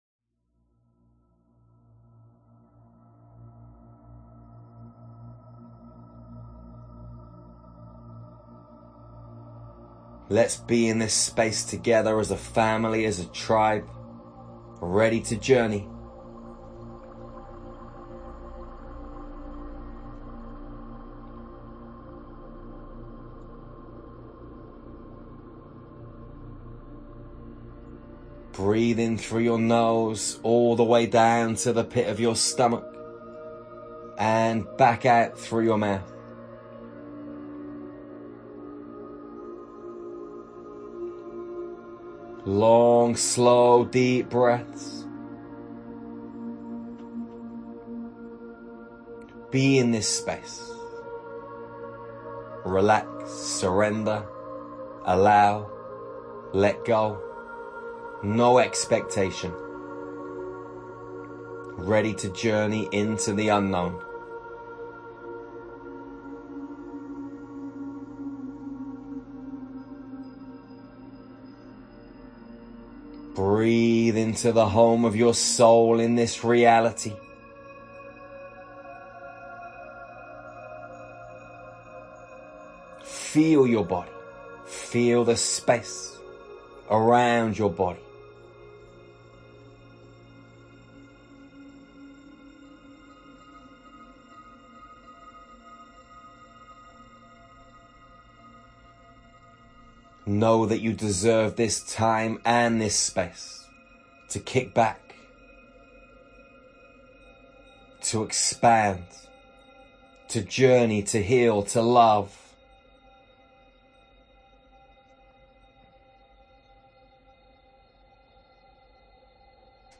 Experience this galactic meditation, and connect deep within to experience healing at a multi-dimensional level.